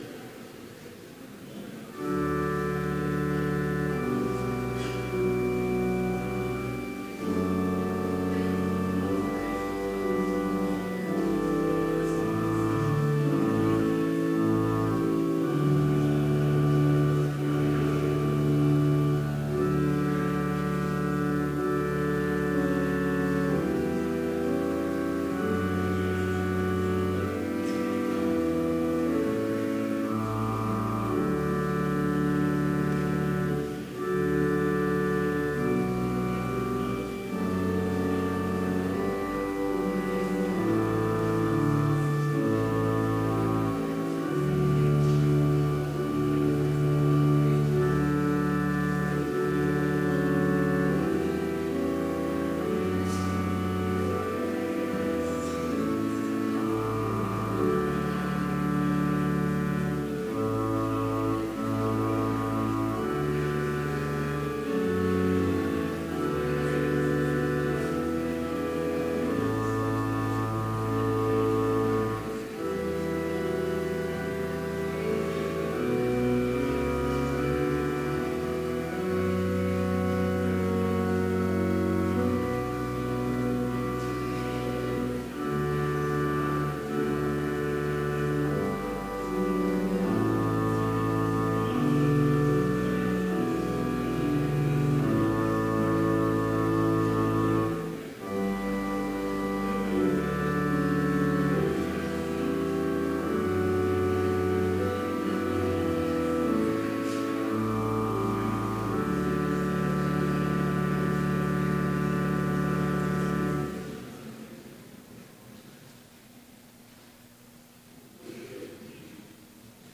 Complete service audio for Chapel - January 12, 2017